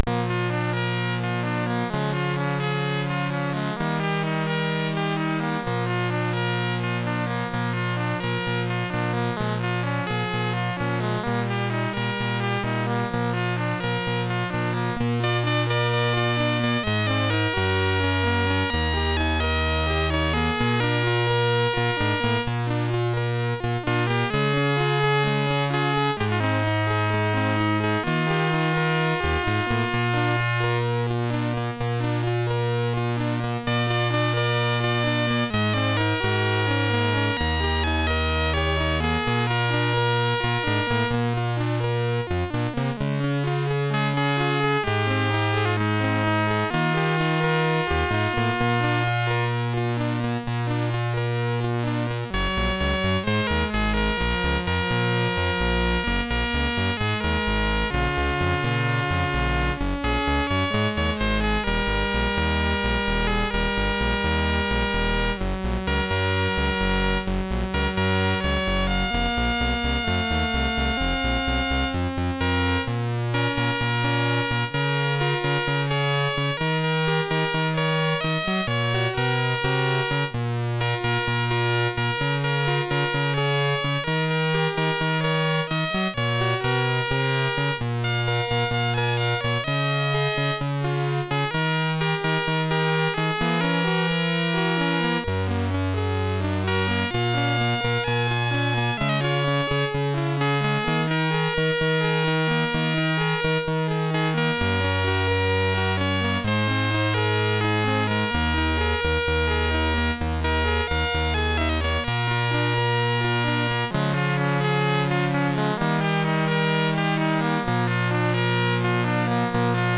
Commodore SID Music File
1 channel